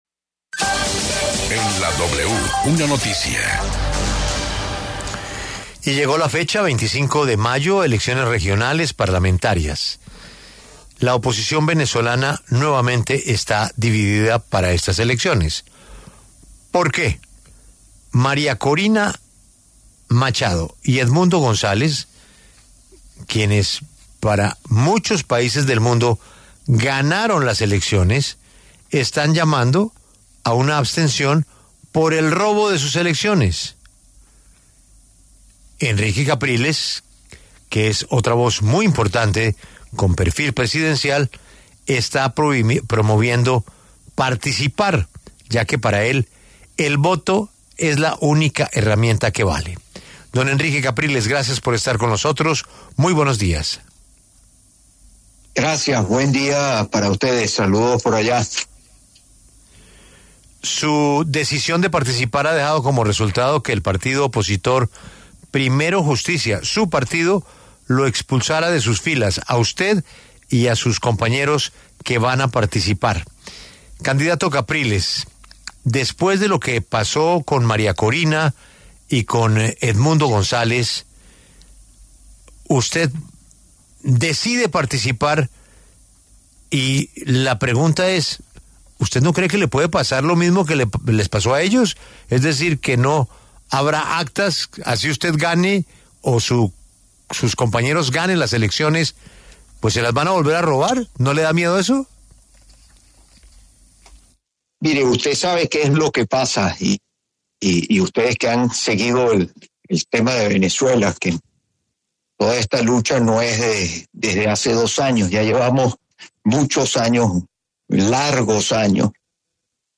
La W conversó con el líder de la oposición venezolana Henrique Capriles, quien pidió que no haya abstención en las elecciones parlamentarias de 2025.